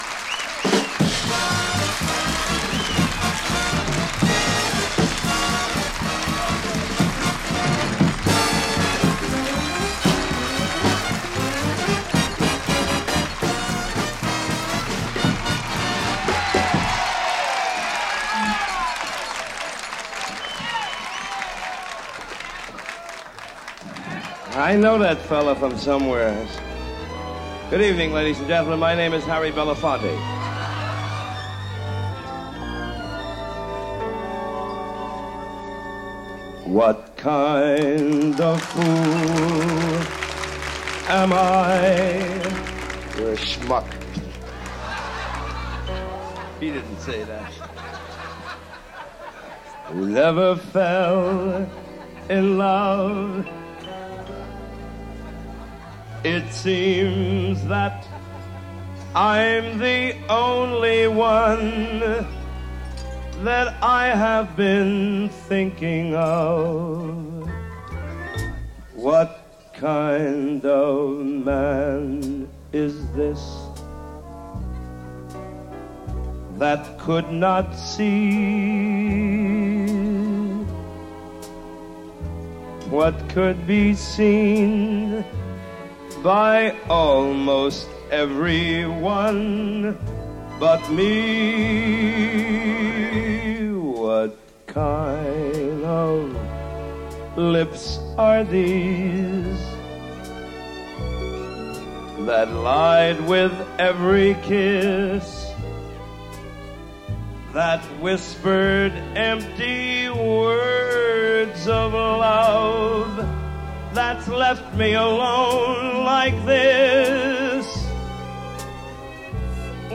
13 Over-the-Top Vocal Performances of The 1960s